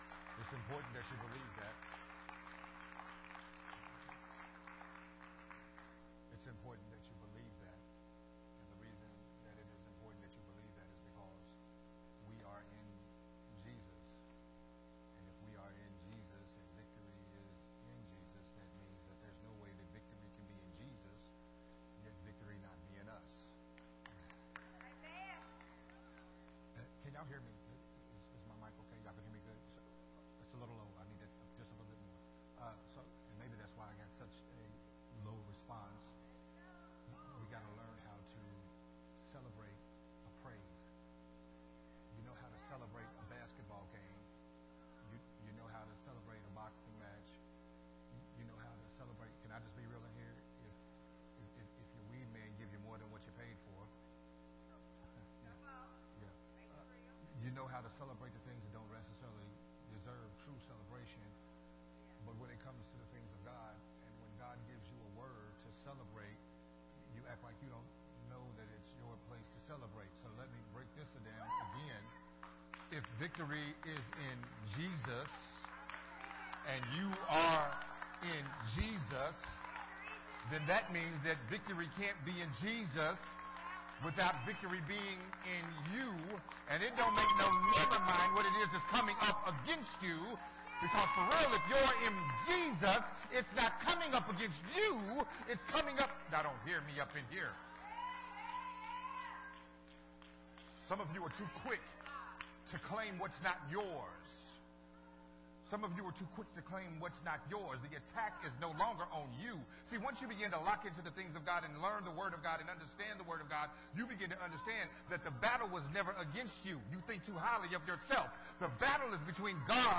Part 4 of the Sermon Series
recorded at Unity Worship Center